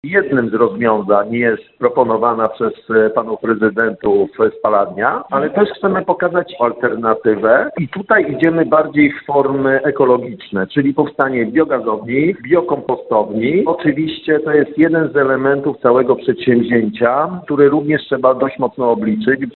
Mówił radny Damian Szwagierczak.